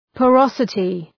{pɔ:’rɒsətı}